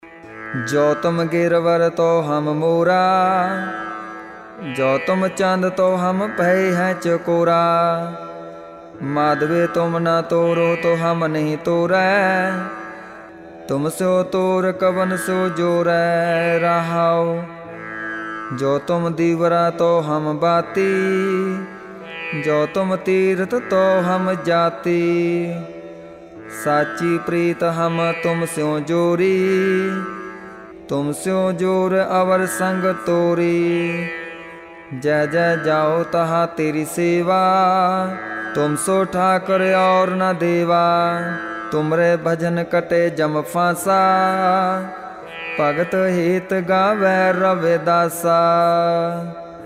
ਰਾਗੁ ਸੋਰਠਿ ਬਾਣੀ ਭਗਤ ਰਵਿਦਾਸ ਜੀ ਕੀ